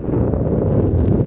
MOVEROCK.WAV